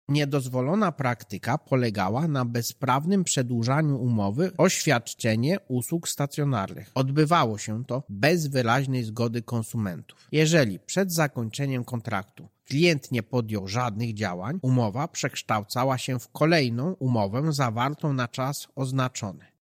Jak to wyglądało w praktyce mówi Marek Niechciał prezes UOKiK.